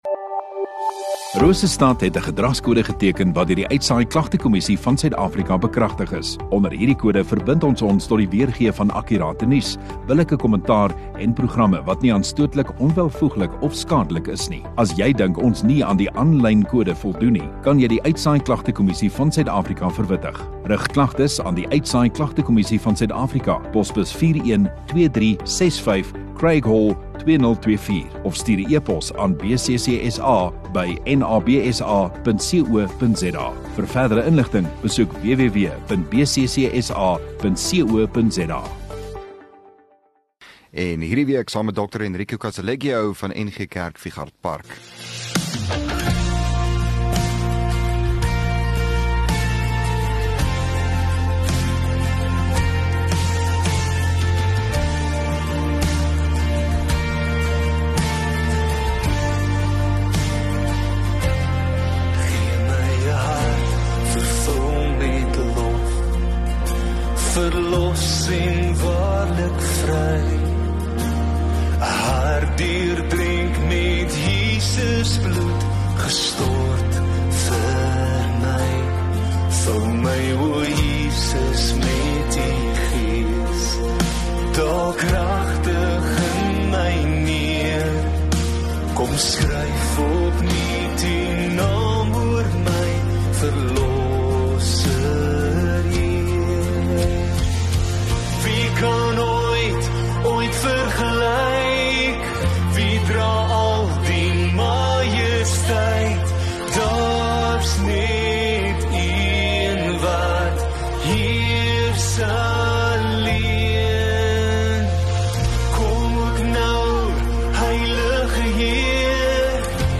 8 May Donderdag Oggenddiens